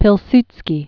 (pĭl-stskē, pē--), Józef Klemens 1867-1935.